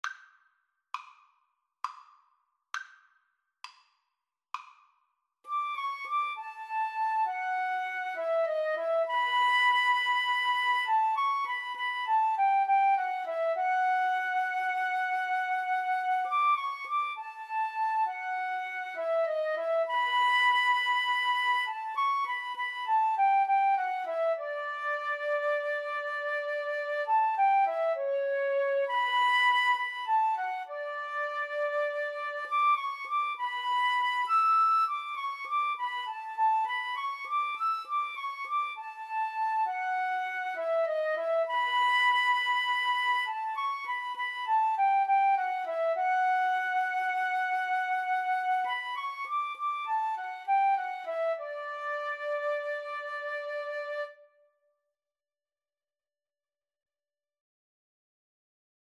Free Sheet music for Flute Duet
D major (Sounding Pitch) (View more D major Music for Flute Duet )
Moderato
9/8 (View more 9/8 Music)